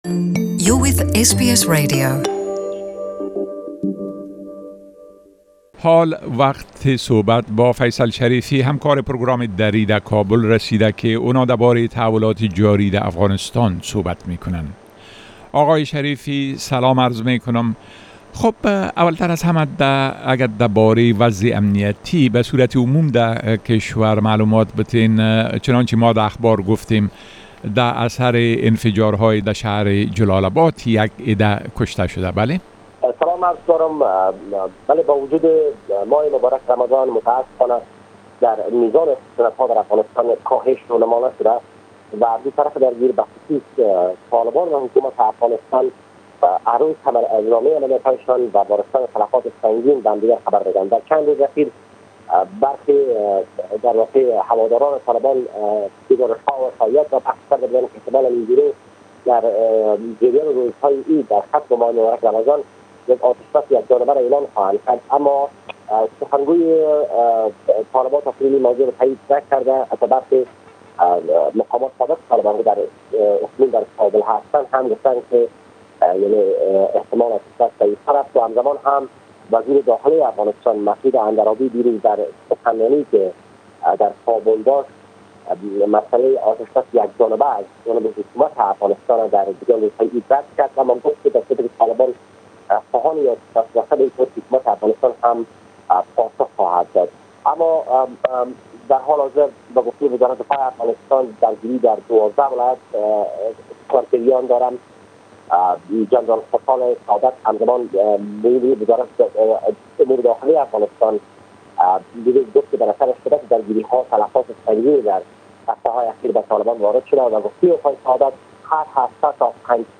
خبرنگار ما در كابل: با وجود ماۀ رمضان كاهشى در جنگها در مناطق مخلتف افغانستان رونما نشده است